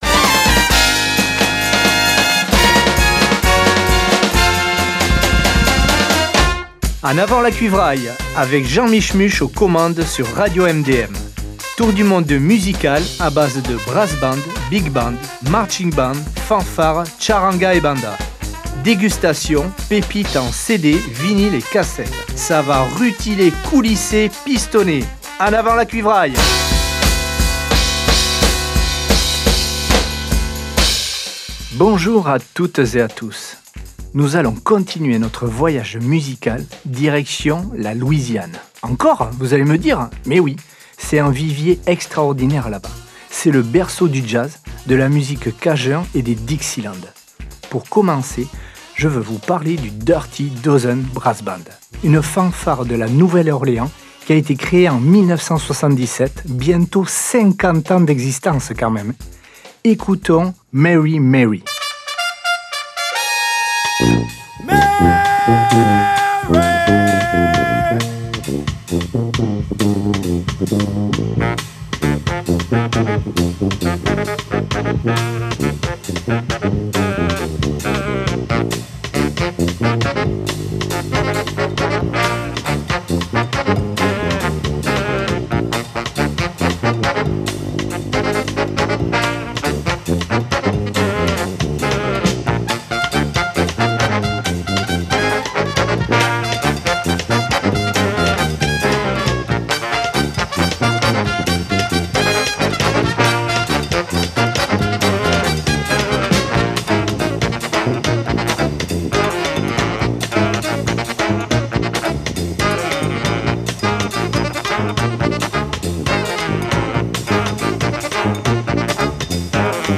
Ça va cuivrer !